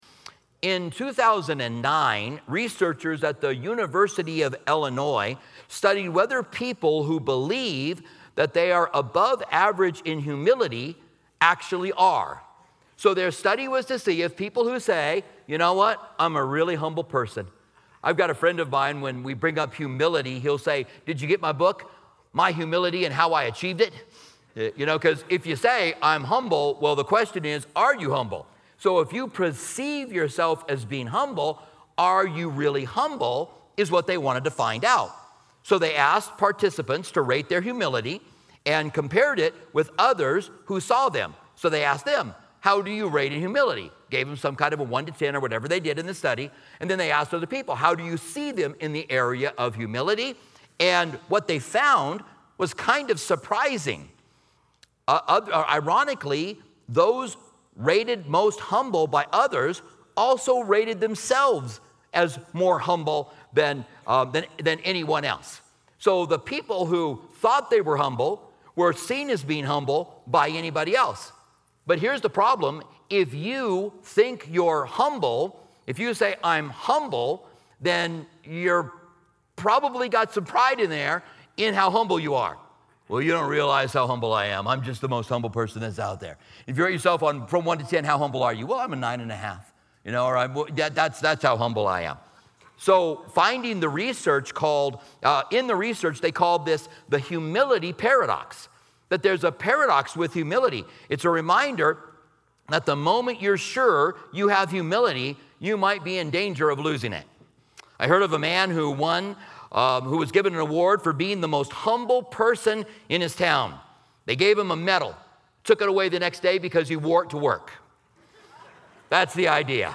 This engaging sermon examines the "humility paradox," where self-perceived humility can lead to pride, supported by studies from the University of Illinois and Harvard Business Review. Discover the vital role of humility within the body of Christ and how each member's unique spiritual gifts—like prophecy, serving, and teaching—should work together to glorify God.